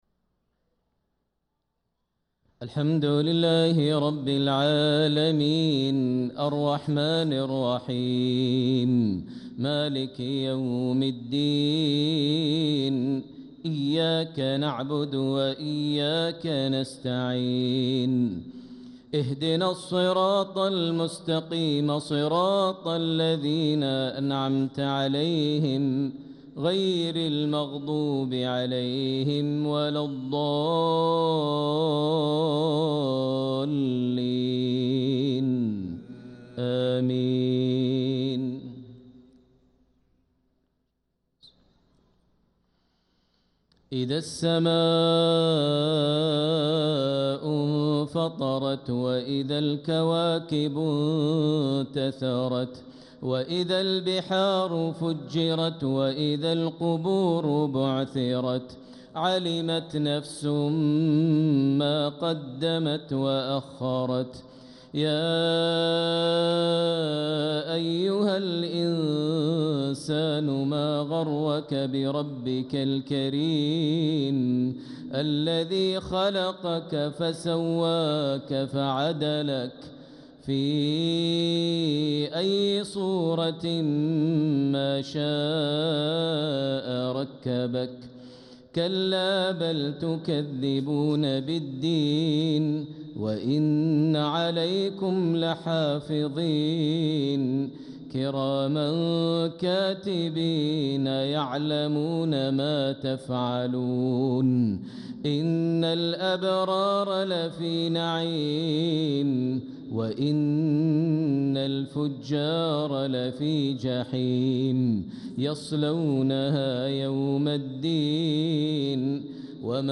Isha prayer from Surah Al-Infitaar and At-Tariq 6-4-2025 > 1446 H > Prayers - Maher Almuaiqly Recitations